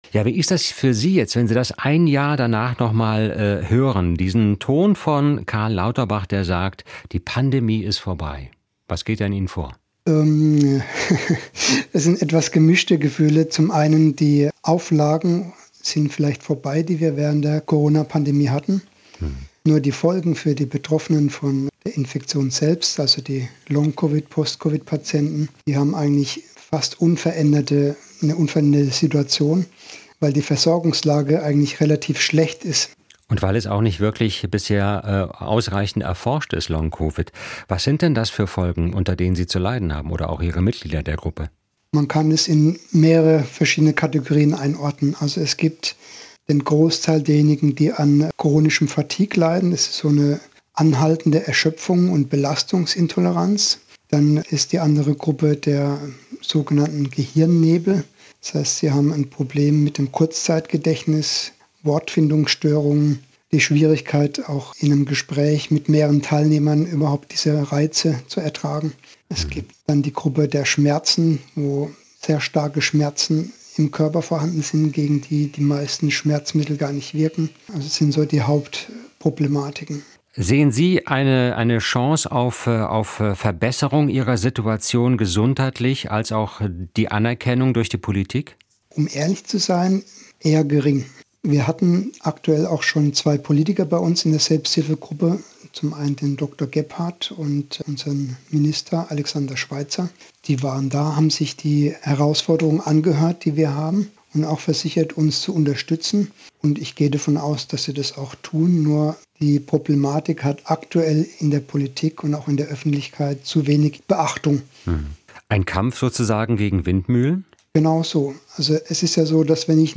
SWR1 Interviews